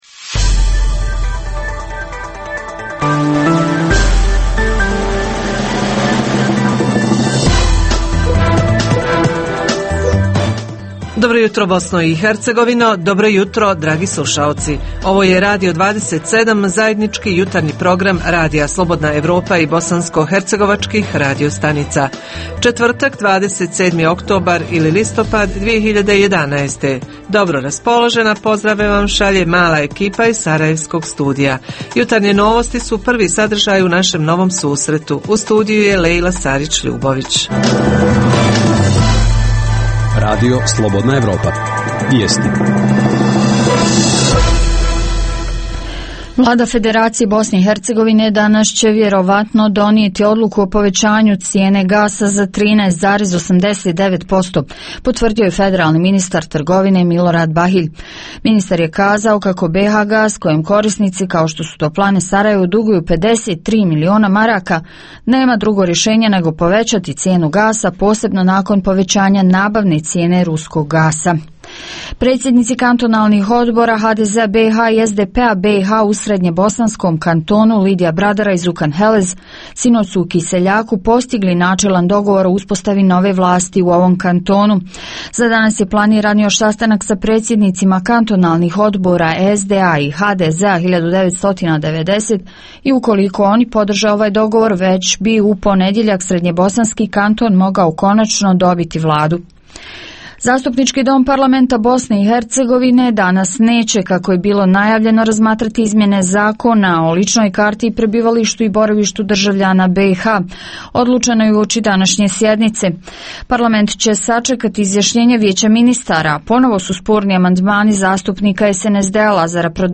Propadaju li male prodavnice i drugi skromni biznisi zato što se otvaraju veliki tržni centri ili zbog toga što opada kupovna moć građana? Reporteri iz cijele BiH javljaju o najaktuelnijim događajima u njihovim sredinama.
Redovni sadržaji jutarnjeg programa za BiH su i vijesti i muzika.